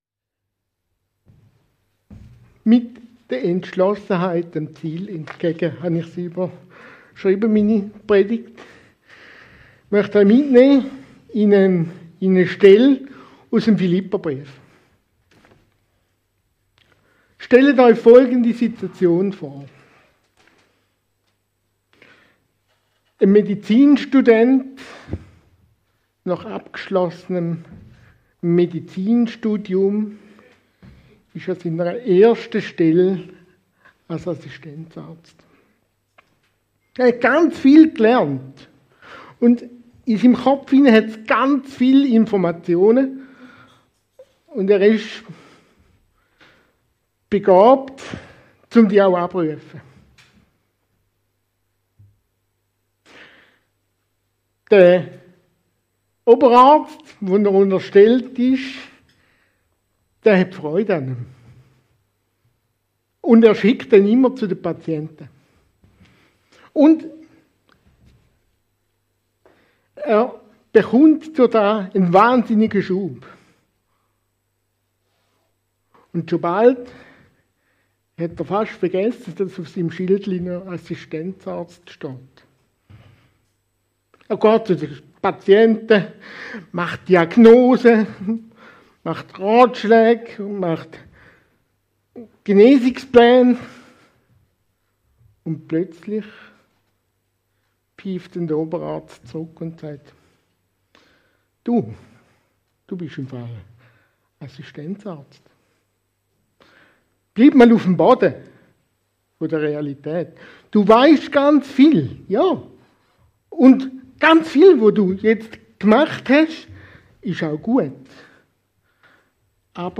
Praktische Anwendung der Predigt